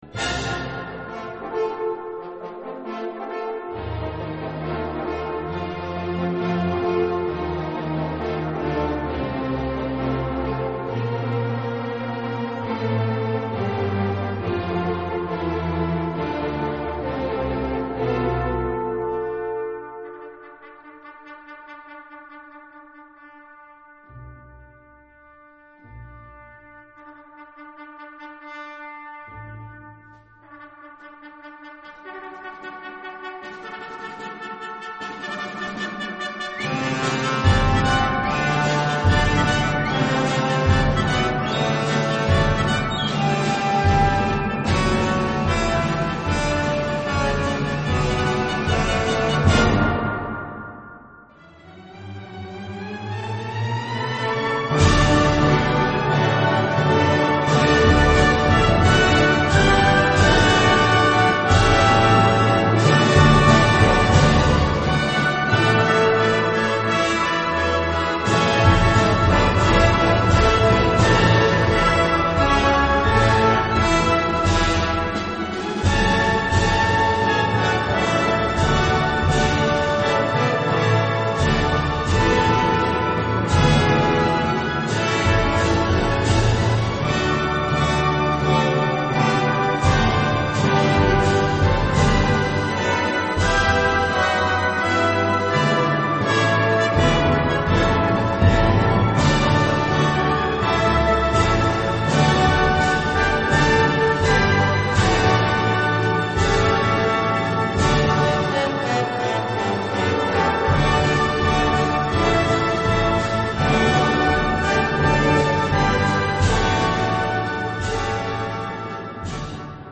ロシアの皇太子がデンマークの王女を妃として迎えるというのでチャイコフスキーにこの曲の依頼がきたというわけである。デンマークの国歌が堂々と歌われ、本当に1812年序曲のデンマーク版という感じがする。何よりもブラスが咆えまくる。
Danish_festival_overture.mp3